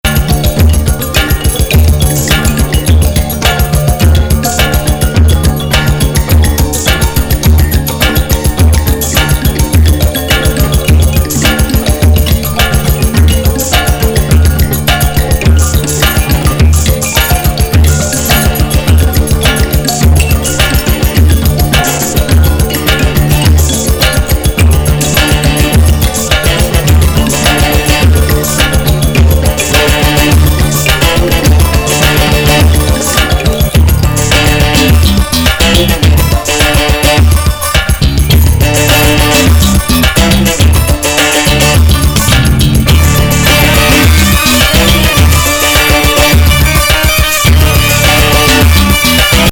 UK NWグループによる88年の12INCH!アラビック・エスノ・トライバル
B面のミックスが◎エスノな上音にソリッドなベースとトライバル・ドラムが
呪術的!